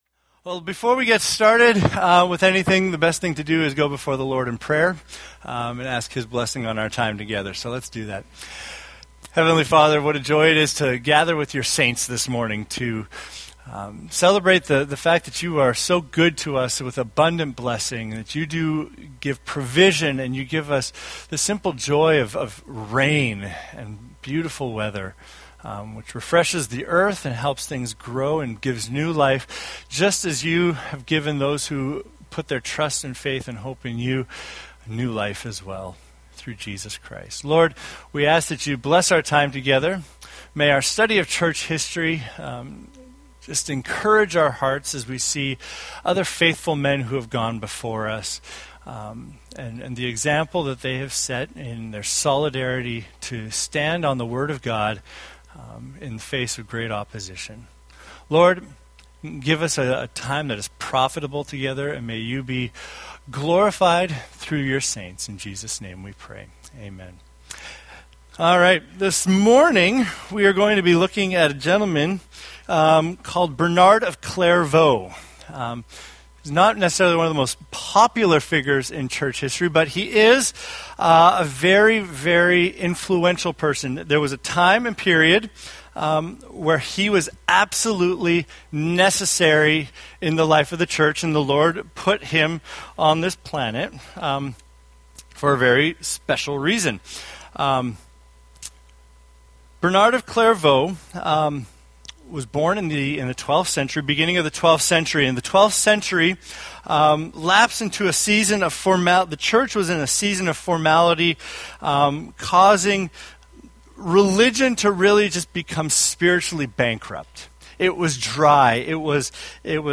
Date: May 17, 2015 Series: Historical Men of Faith Grouping: Sunday School (Adult) More: Download MP3